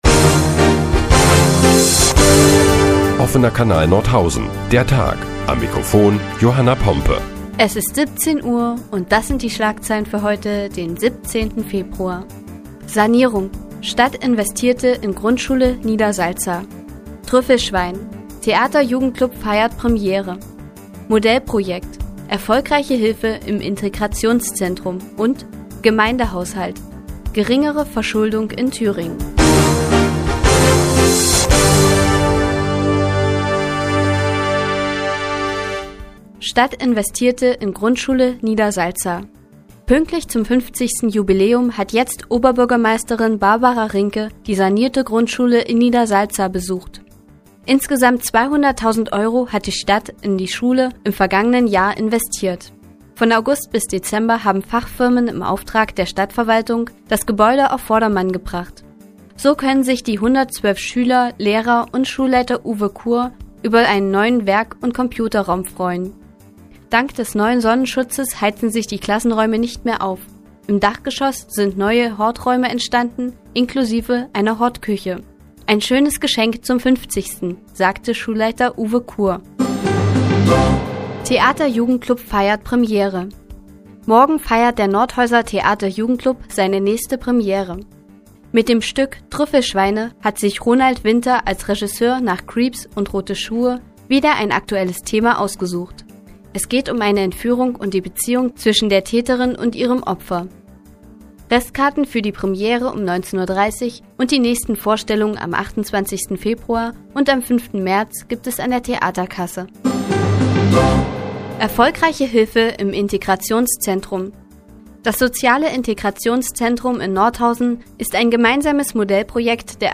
Die tägliche Nachrichtensendung des OKN ist nun auch in der nnz zu hören. Heute geht es unter anderem um Trüffelschweine und den Gemeindehaushalt.